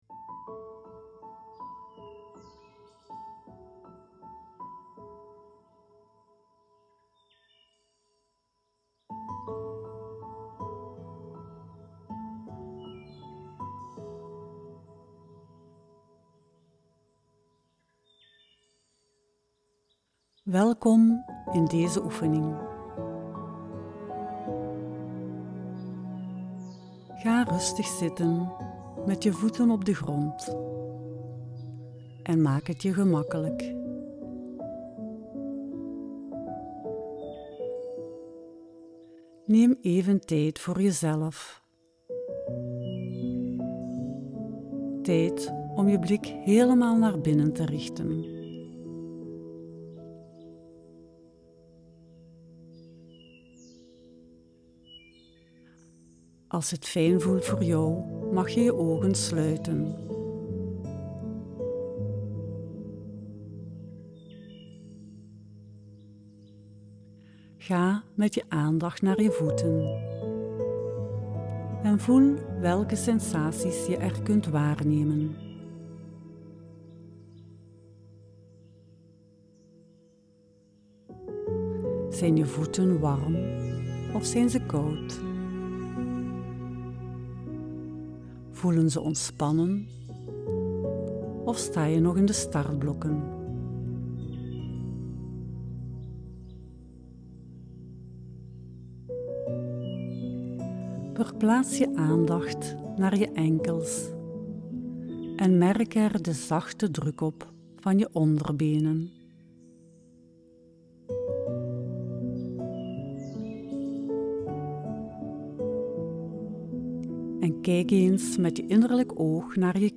actieve meditatie